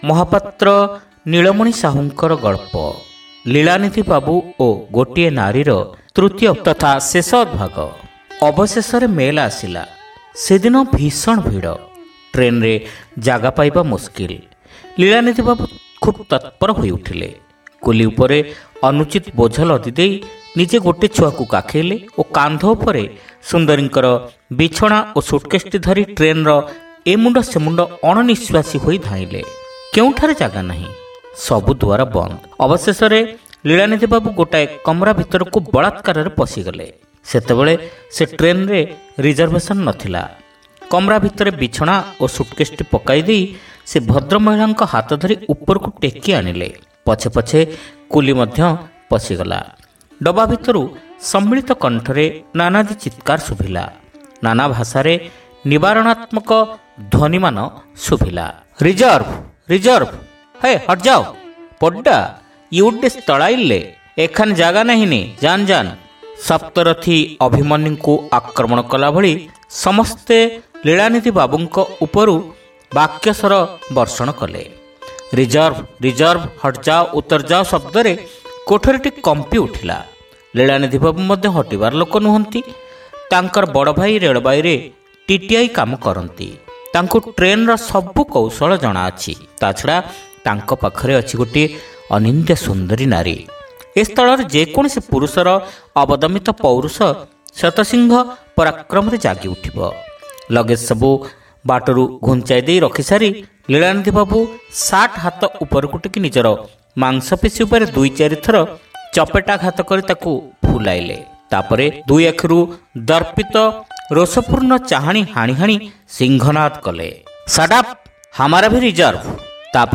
ଶ୍ରାବ୍ୟ ଗଳ୍ପ : ଲୀଳାନିଧି ବାବୁ ଓ ଗୋଟିଏ ନାରୀ (ତୃତୀୟ ଭାଗ)